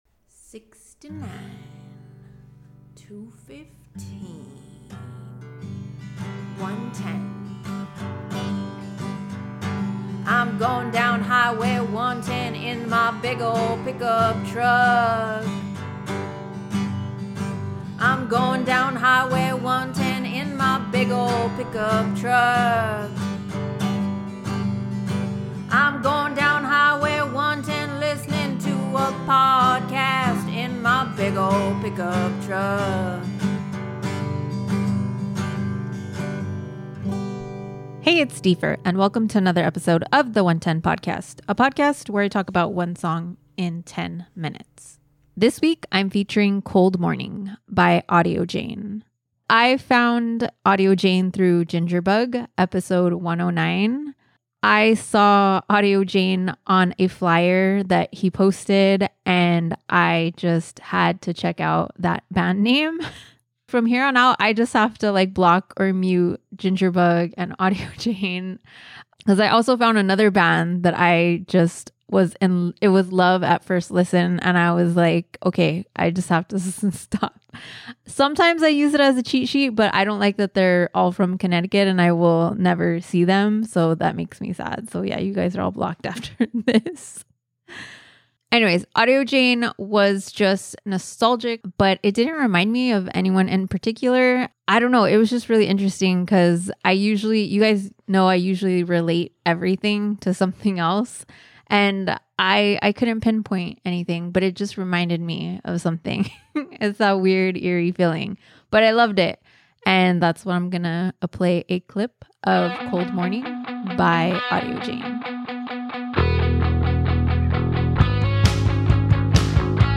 a “female-fronted